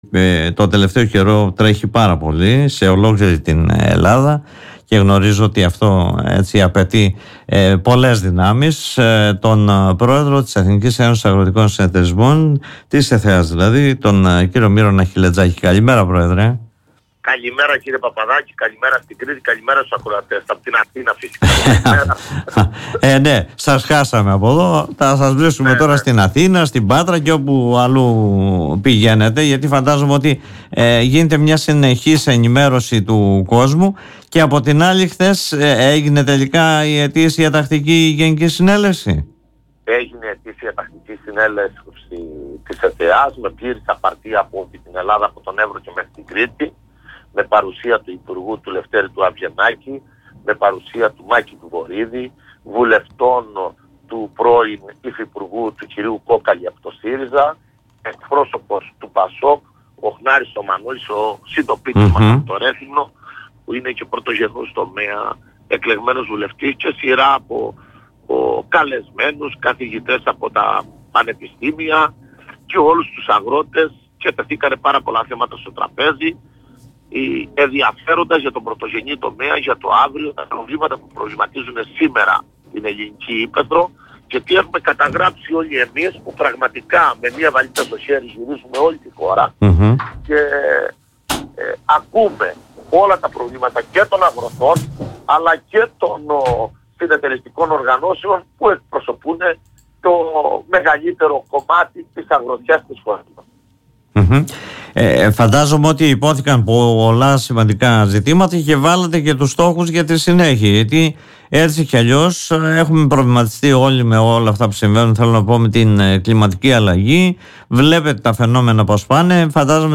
Την αποκάλυψη πως είναι προ των πυλών η δημιουργία μιας νέας αγρονομικής περιφέρειας ελαιοκαλλιέργειας, μέσω του υπουργείου Αγροτικής Ανάπτυξης και Τροφίμων, έκανε μέσα από την εκπομπή “Δημοσίως” του politica 89,8